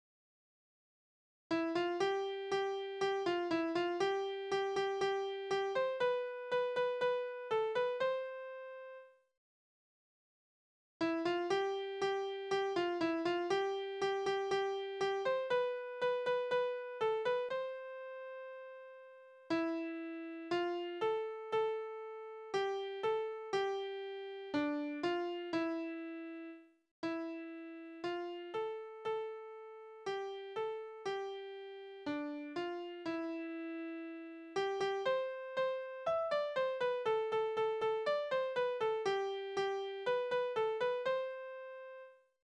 Balladen: Das verlassene Mädchen geht ins Wasser
Tonart: C-Dur
Taktart: 4/4
Tonumfang: kleine Septime
Besetzung: vokal